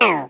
Sfx_tool_spypenguin_vo_hit_wall_02.ogg